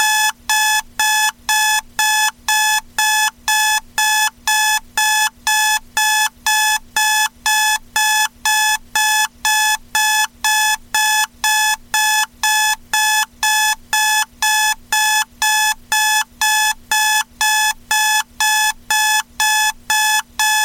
loud-alarm-clock_24902.mp3